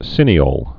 (sĭnē-ōl)